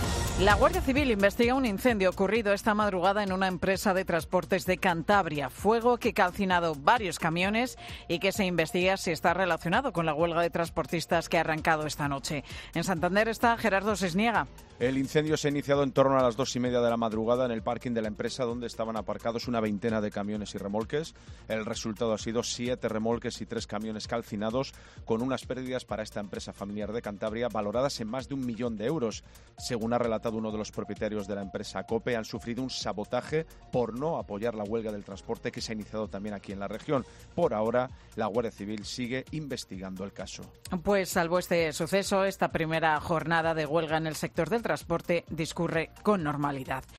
La Guardia Civil investiga un sabotaje por no secundar la huelga del transporte. Crónica